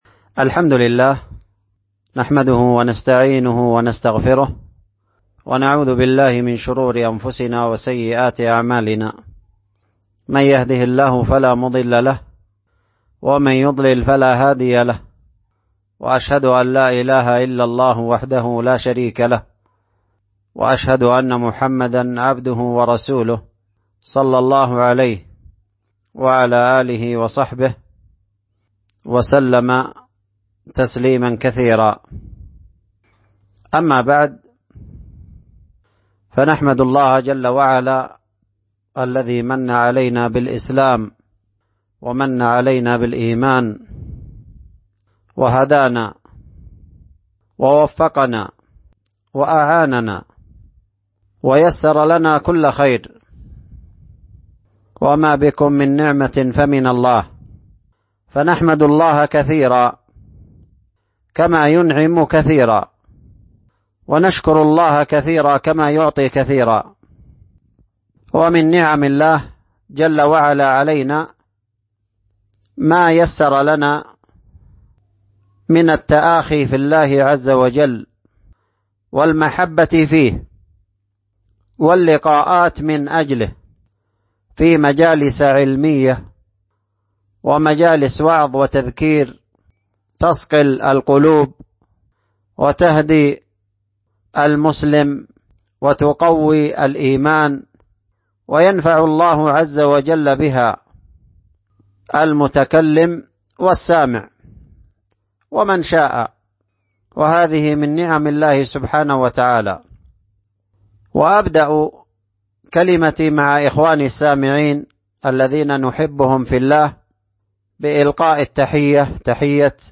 محاضرة بعد صلاة العشاء عبر الهاتف (مفتوحة) ، حول قوله تعالى: ( قد افلح من زكاها . وقد خاب من دساها) ، وفيها بيان أهمية تزكية النفس، ووسائل ذلك، وثمراته على الفرد والجماعة .